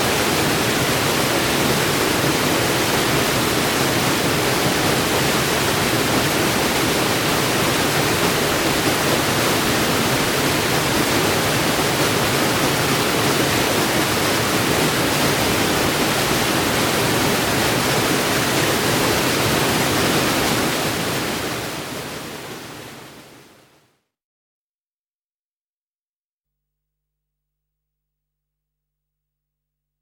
5 -- Une cascade